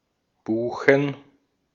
Ääntäminen
Ääntäminen Tuntematon aksentti: IPA: /buː.χən/ Haettu sana löytyi näillä lähdekielillä: saksa Käännöksiä ei löytynyt valitulle kohdekielelle. Buchen on sanan Buche monikko.